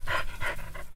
esniff1.ogg